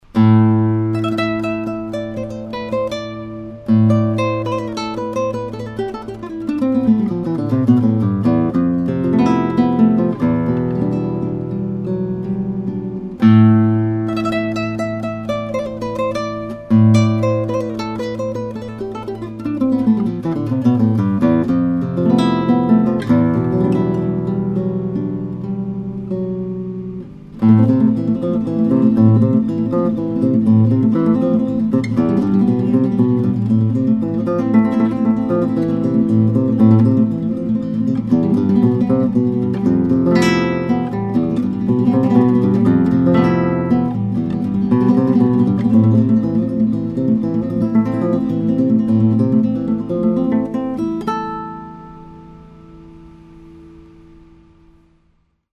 It’s got that warm and full Spanish sound that you expect from a Ramirez.